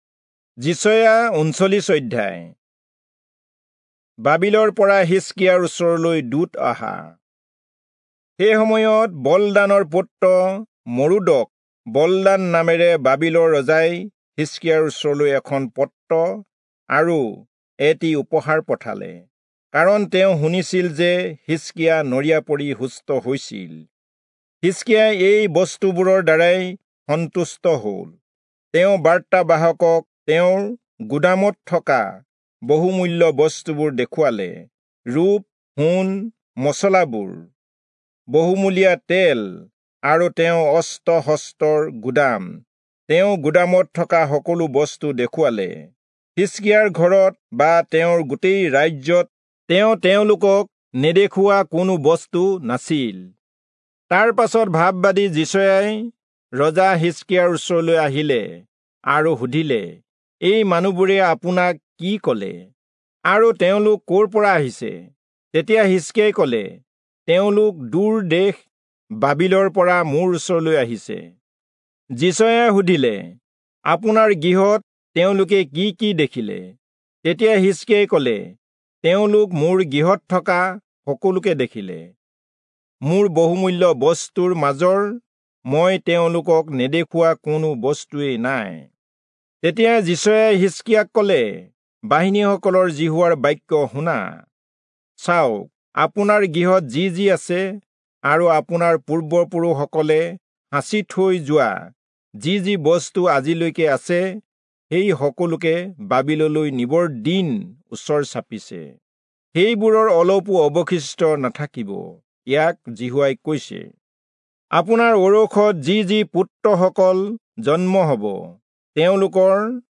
Assamese Audio Bible - Isaiah 4 in Urv bible version